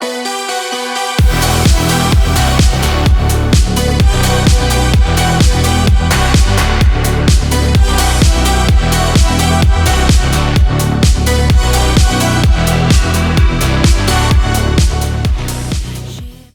громкие
Synth Pop
Синти поп проигрыш